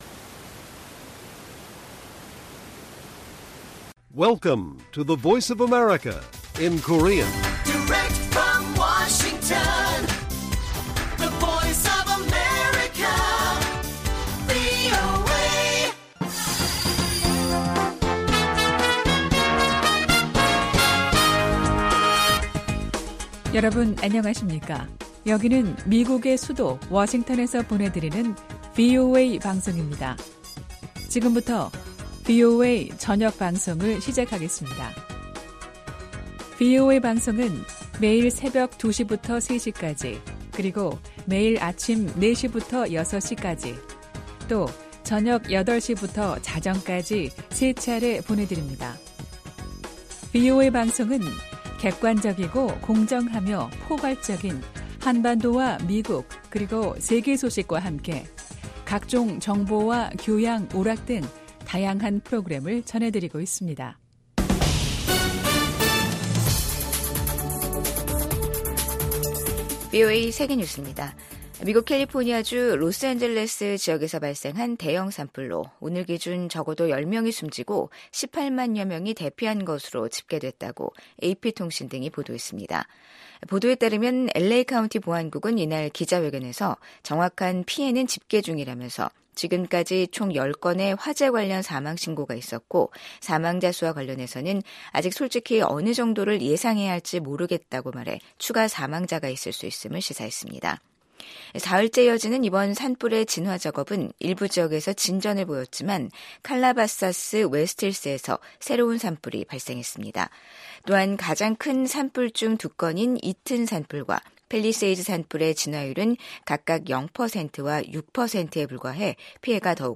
VOA 한국어 간판 뉴스 프로그램 '뉴스 투데이', 2025년 1월 10일 1부 방송입니다. 미국 제 39대 대통령을 역임한 지미 카터 전 대통령의 장례식이 9일 워싱턴 DC에서 국장으로 엄수됐습니다. 100세를 일기로 별세한 지미 카터 전 미국 대통령의 한반도 관련 유산은 상반된 평가를 받고 있습니다. 우크라이나 전쟁에 파견된 북한군 사상자가 속출하고 있다는 소식이 전해지고 있는 가운데 북한은 여전히 파병 사실 자체를 확인도 부인도 하지 않고 있습니다.